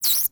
sfx_bunny_squeak_v1.ogg